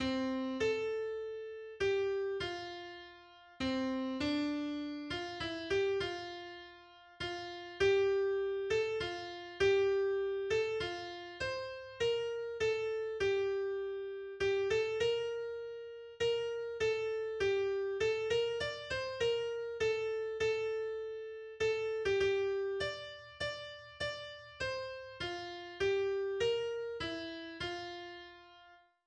Das Abendlied